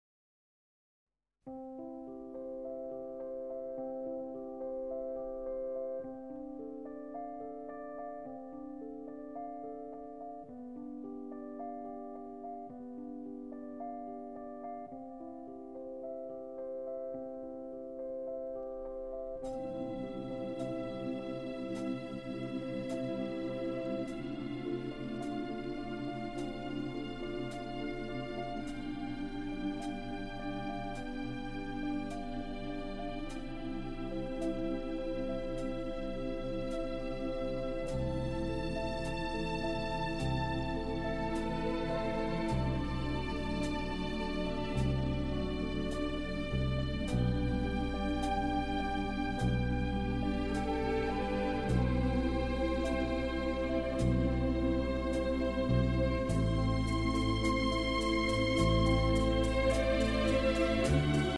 Super Instrumental